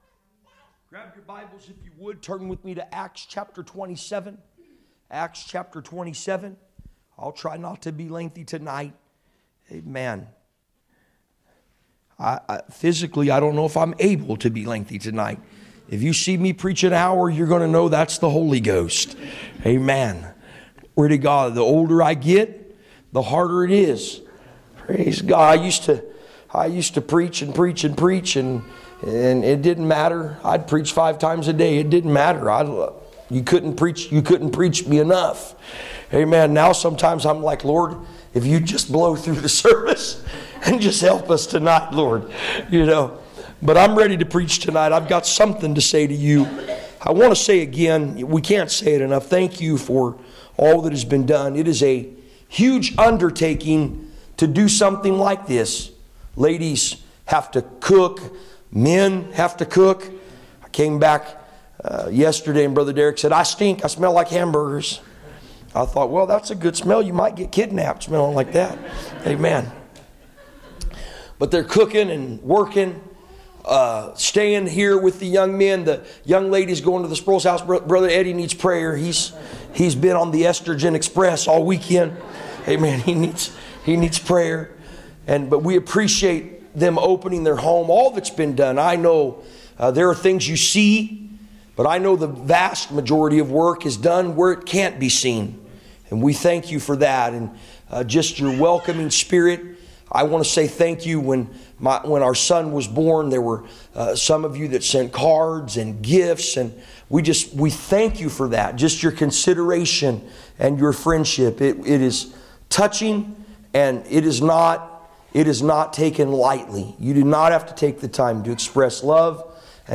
Acts 27:9-20 Service Type: Special event %todo_render% « Weight of His Glory The Door